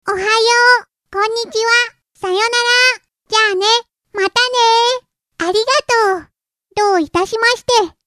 ★ちびキャラボイス館　無料ボイス素材
ちびキャラ・幼女キャラなどをイメージした素材につき、イラッ!!とする恐れがあります。
●サンプルは試聴用にＢＧＭをつけてつなげていますが、ＺＩＰは各セリフ切り分けておりＢＧＭはありません。
あいさつ